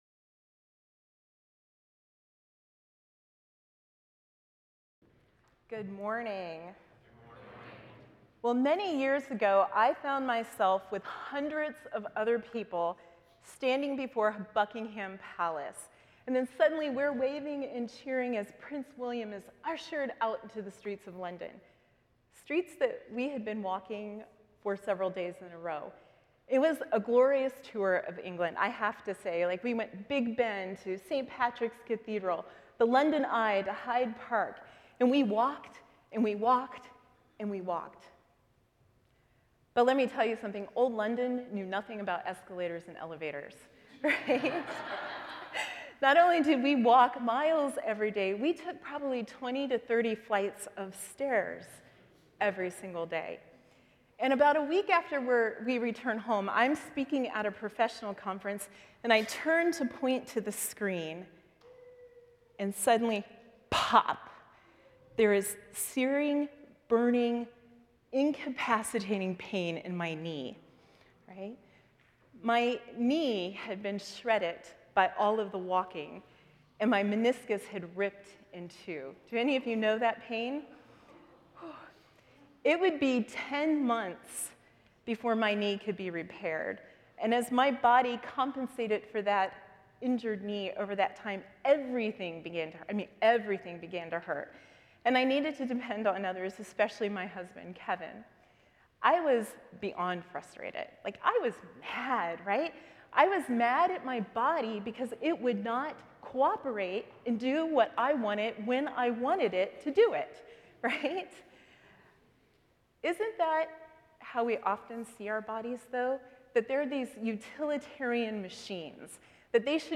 The following service took place on Wednesday, February 4, 2026.
Sermon